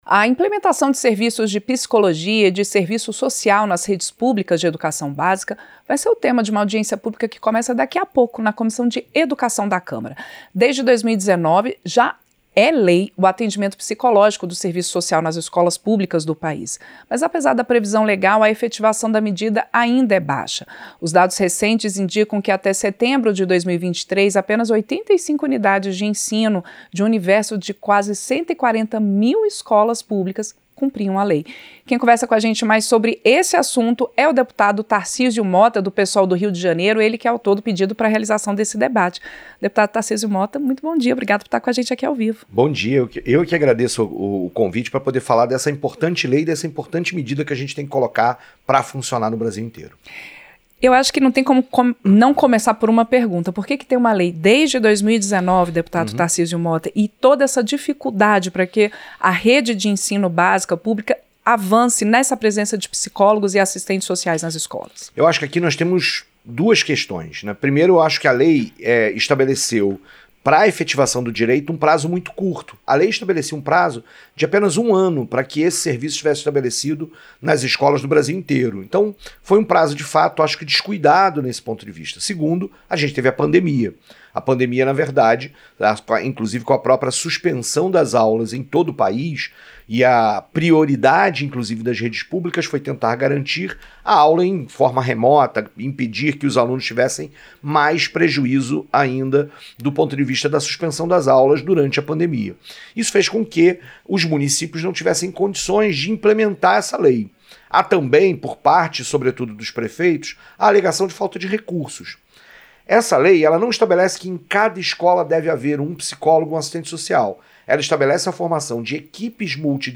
Entrevista - Dep. Tarcísio Motta (PSOL-RJ)